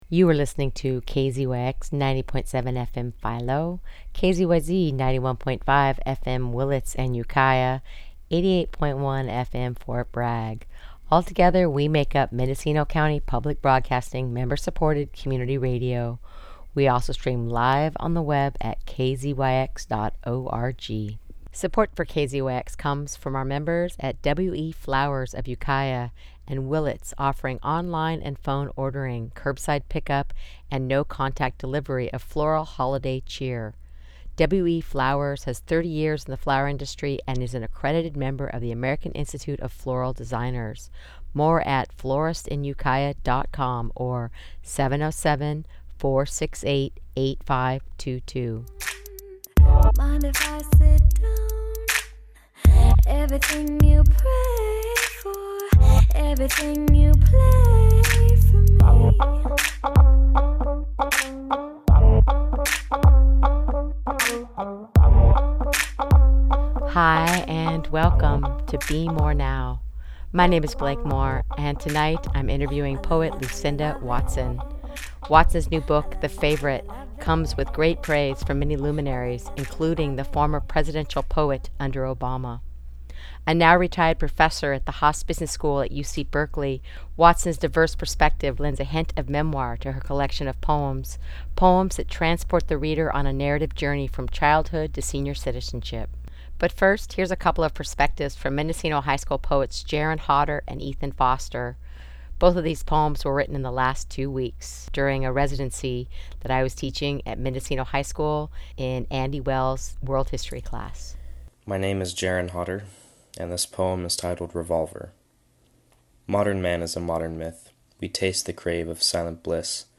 Interview Featured on NPR